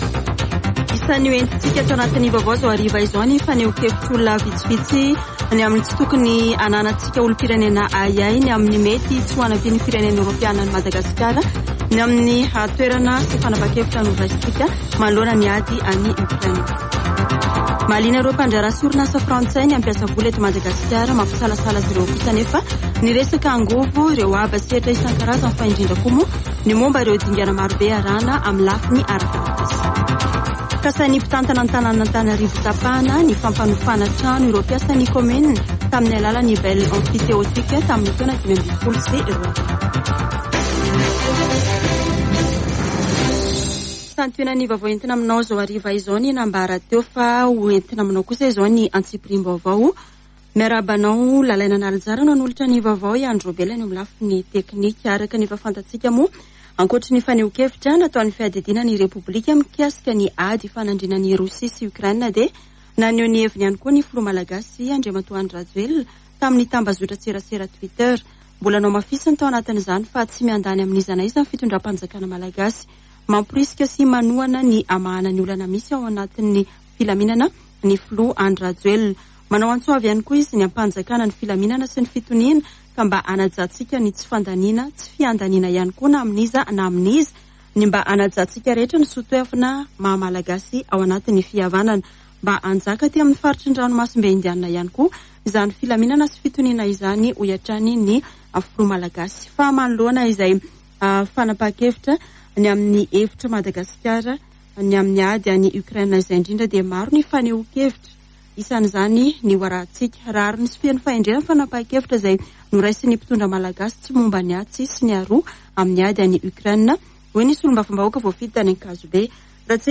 [Vaovao hariva] Zoma 4 marsa 2022